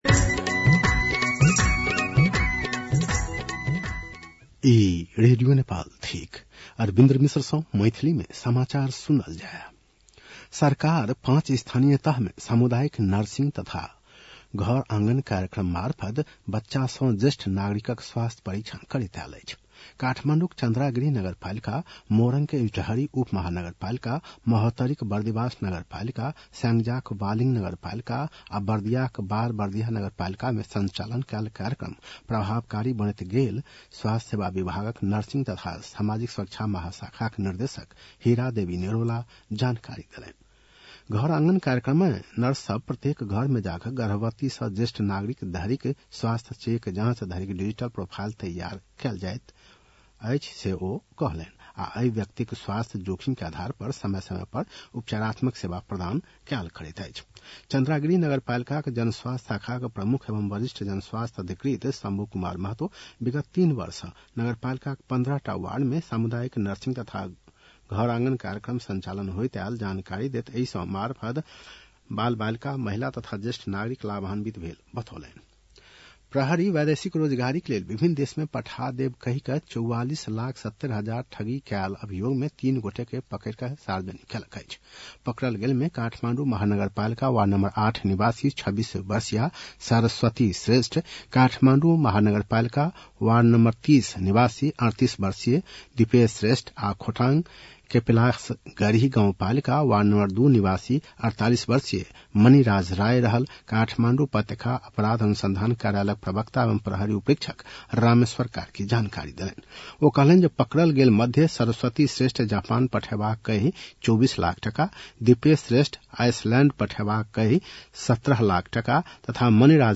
मैथिली भाषामा समाचार : ६ फागुन , २०८२